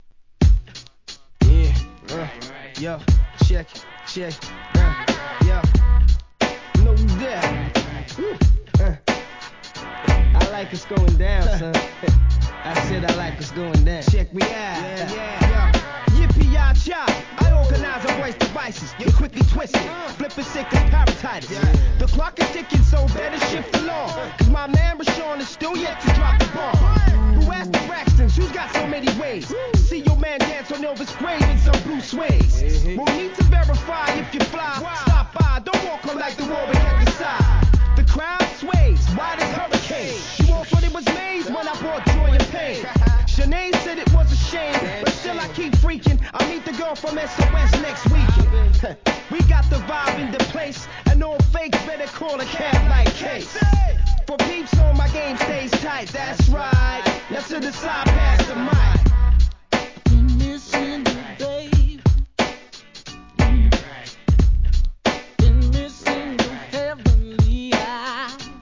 HIP HOP/R&B
ソウルフルなヴォーカル！